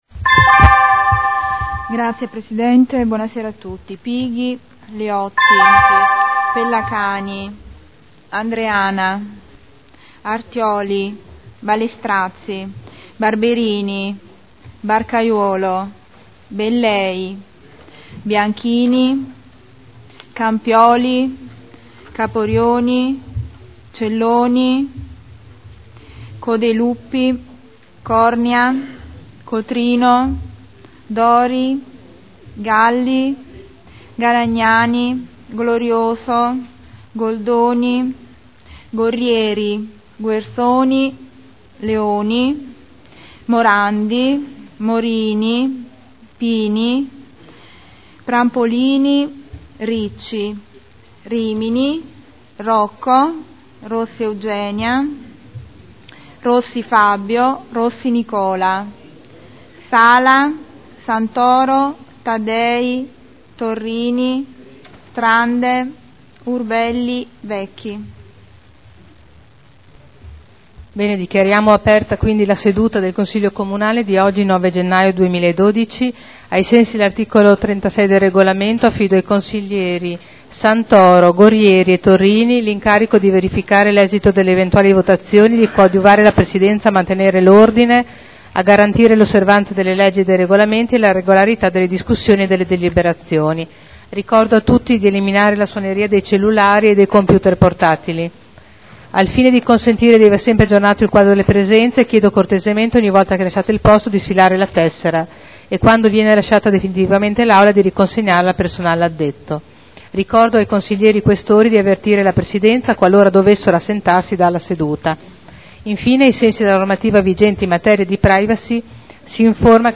Segretario — Sito Audio Consiglio Comunale
Appello